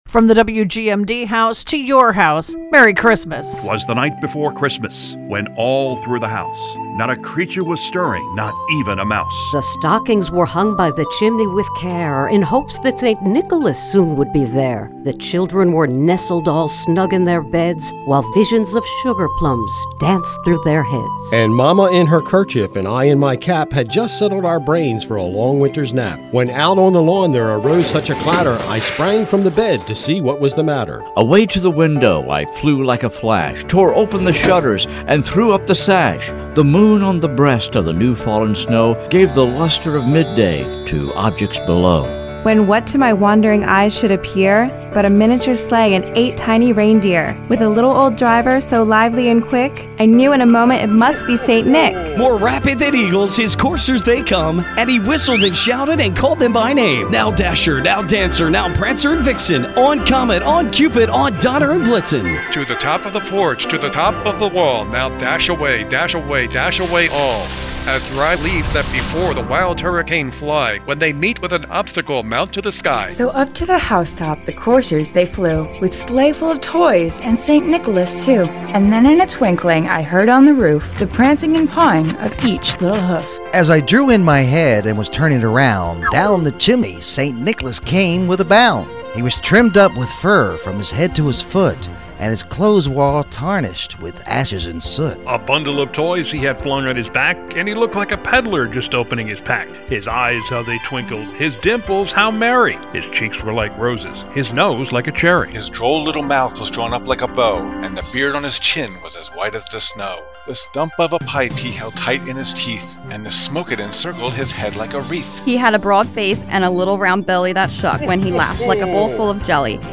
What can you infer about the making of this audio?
This is a legacy recording with a listing of the voices heard below: